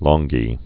(lŏnggē, lng-)